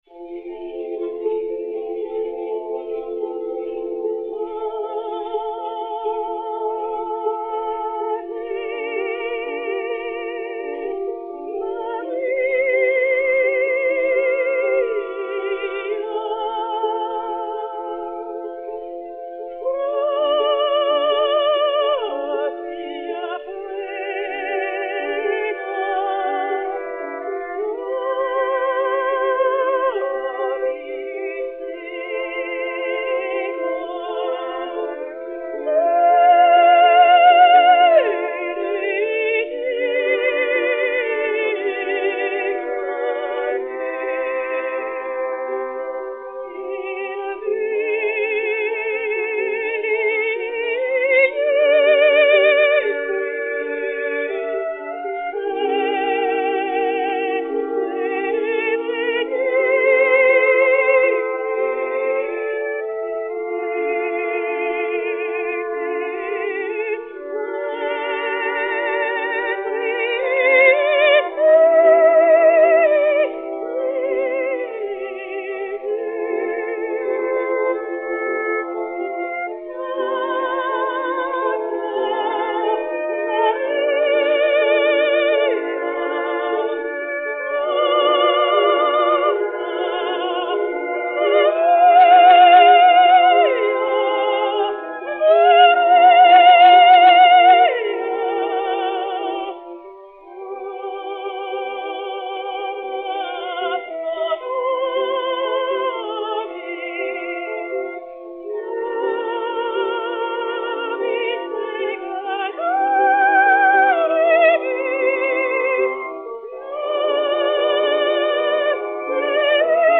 Berthe Auguez de Montalant, Violon, Orgue et Piano
Disque Pour Gramophone 33649, mat. 5112h, enr. à Paris en 1907